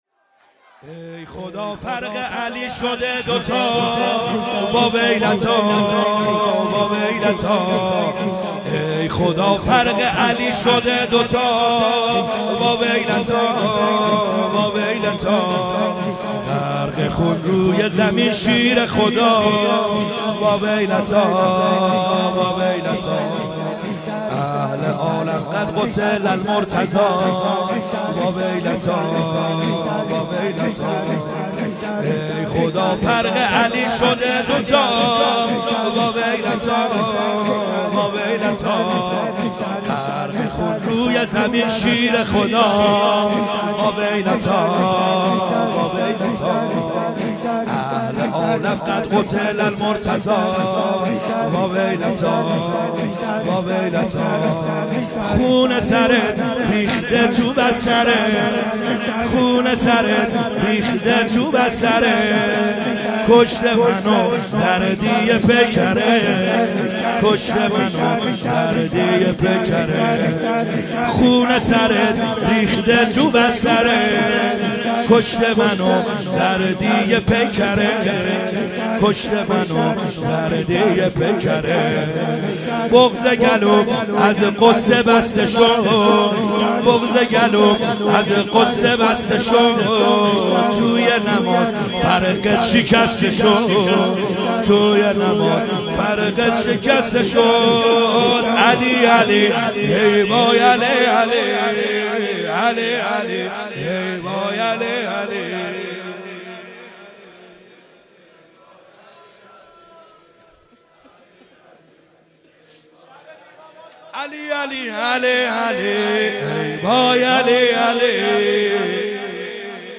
خیمه گاه - بیرق معظم محبین حضرت صاحب الزمان(عج) - لطمه زنی | ای خدا فرق علی شده